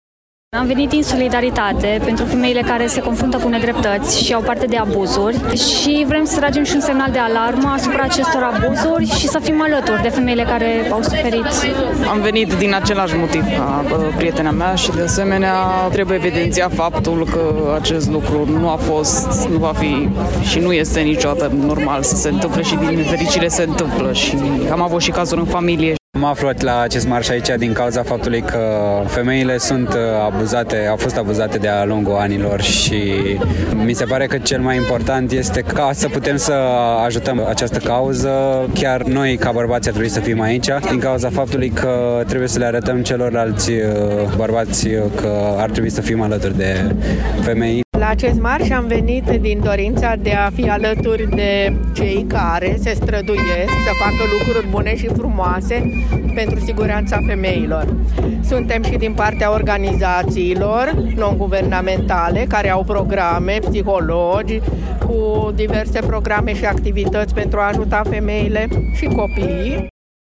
Sute de persoane s-au reunit, ieri, la Brașov, la „Marșul pentru Siguranța Femeilor”
Oamenii au scandat lozinci împotriva violenței, a violului și a altor tipuri de abuzuri la care sunt supuse femeile: